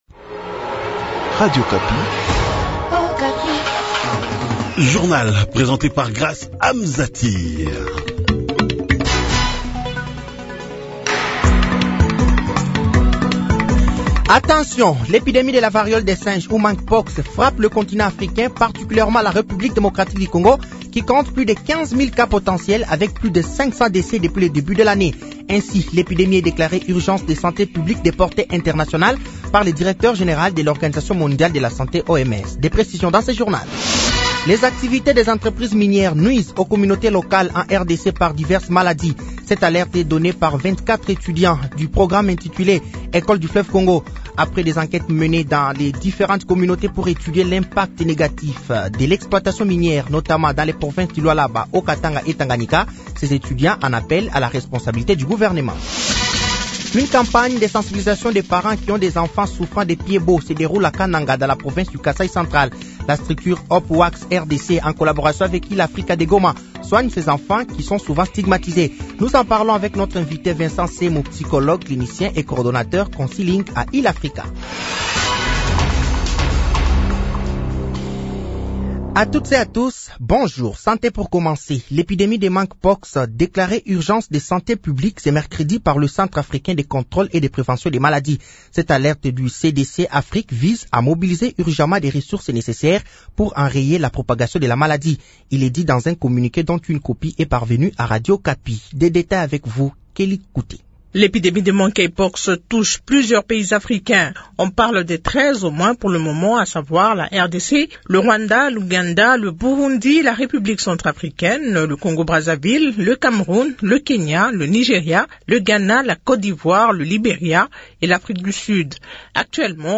Journal français de 7h de ce jeudi 15 août 2024